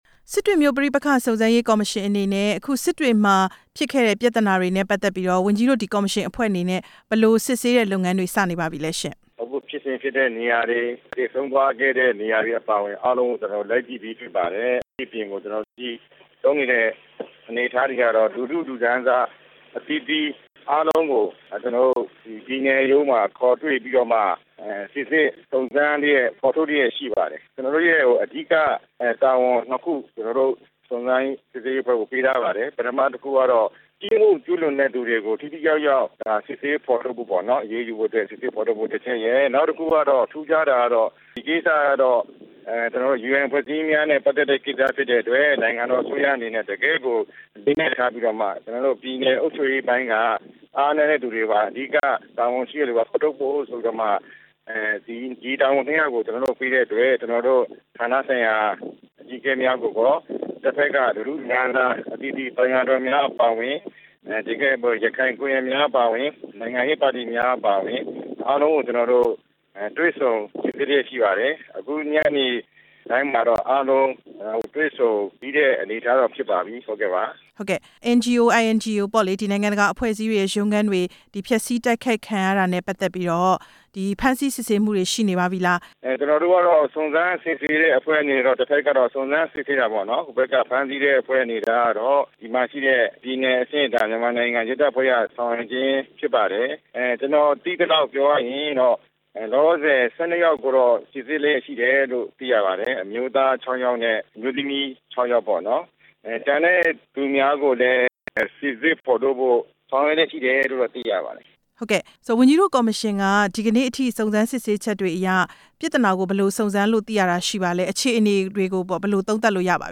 စစ်တွေပဋိပက္ခ စုံစမ်းစစ်ဆေးရေး ကော်မရှင် ဥက္ကဌနဲ့ မေးမြန်းချက်